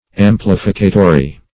Search Result for " amplificatory" : The Collaborative International Dictionary of English v.0.48: Amplificatory \Am*plif"i*ca*to*ry\, a. Serving to amplify or enlarge; amplificative.